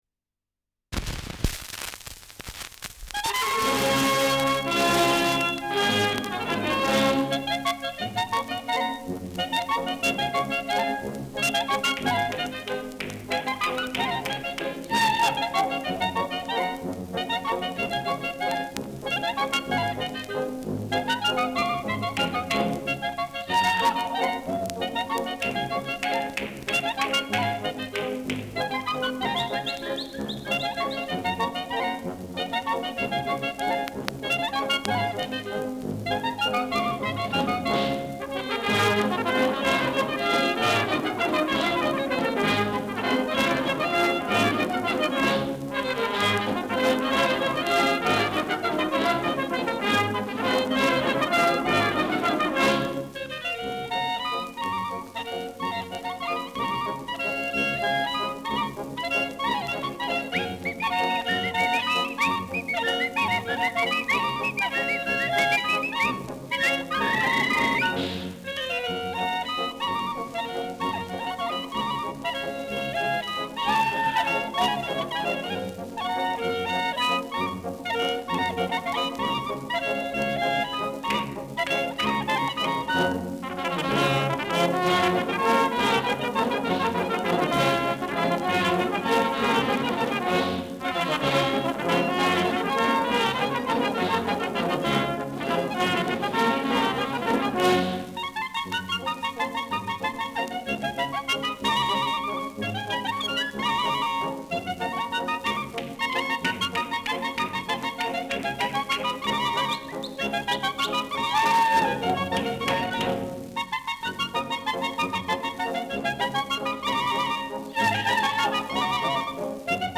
Schellackplatte
Gelegentliches leichtes Knacken : Teils schriller Klang
[Berlin] (Aufnahmeort)